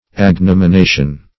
Search Result for " agnomination" : The Collaborative International Dictionary of English v.0.48: Agnomination \Ag*nom`i*na"tion\, n. [L. agnominatio.